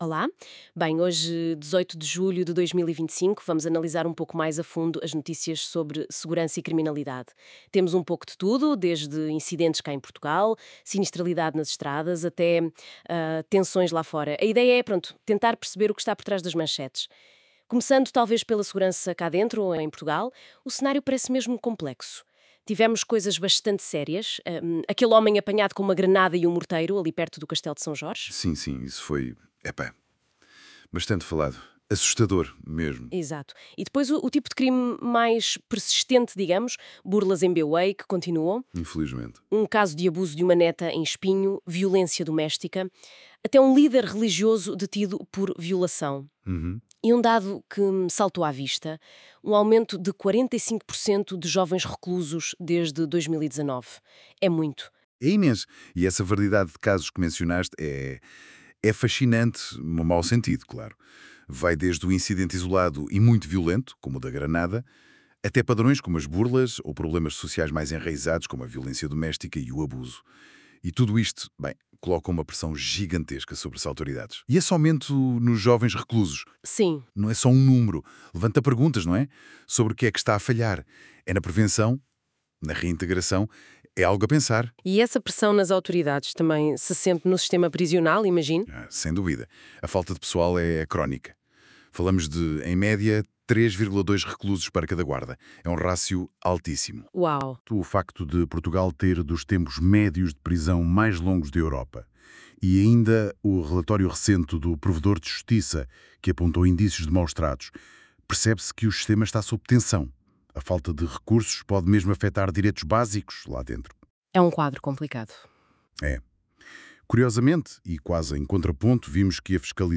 Resumo áudio:
daily-bulletin_-security-and-crime-briefing-1.wav